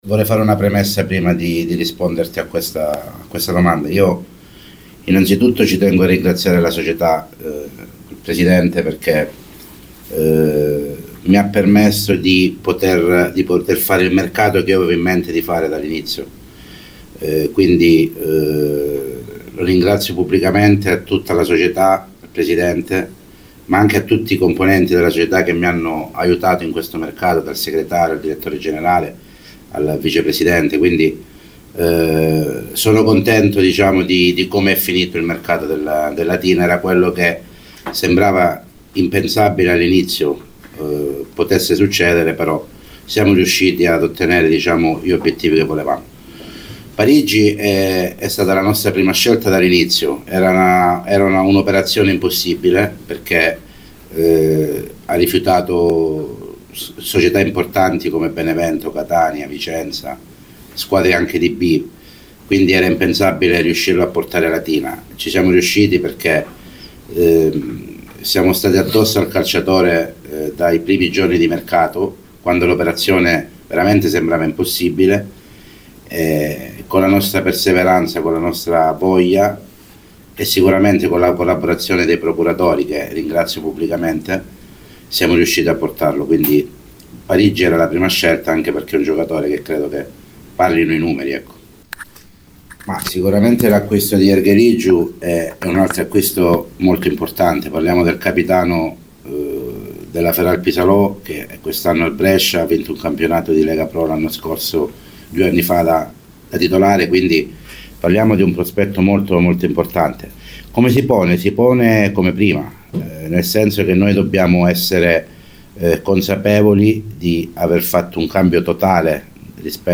nella conferenza stampa tenuta questa mattina